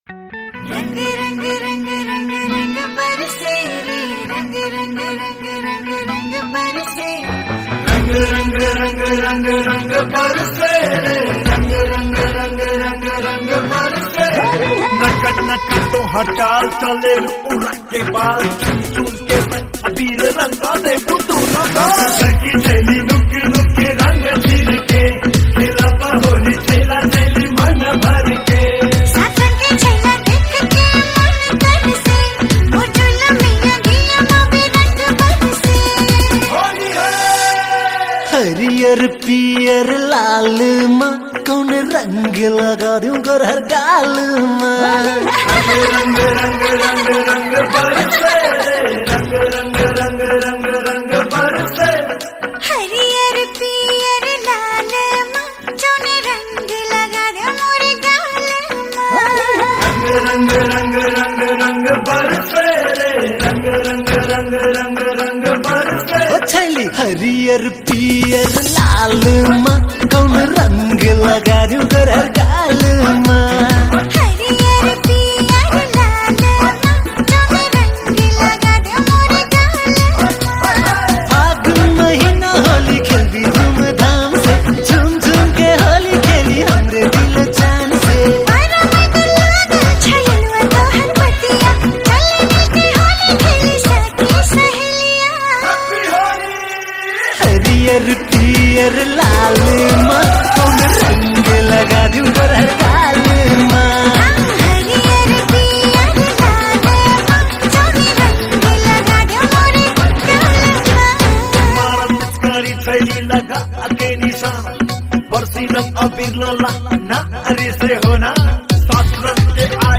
Tharu Holi Song